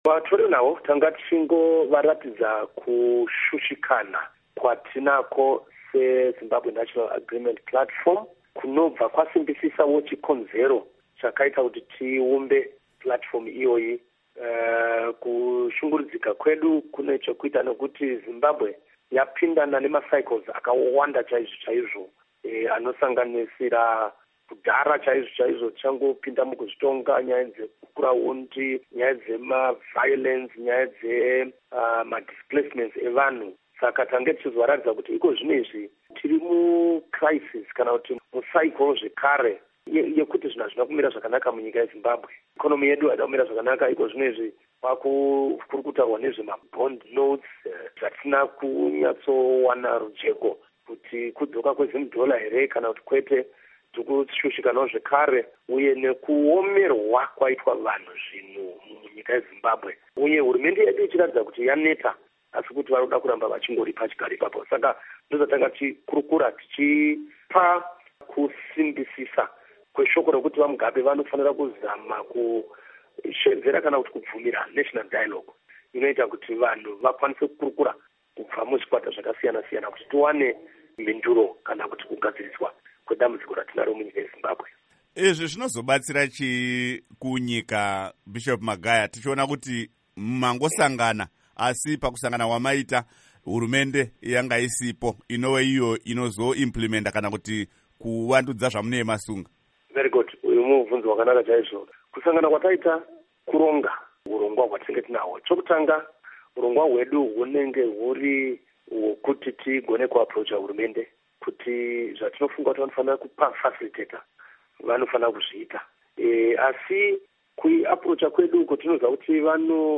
Izvi zvabuda pamusangano waita nesangano re Zimbabwe National Agreement Platform, ZANP, nevatori venhau muHarare.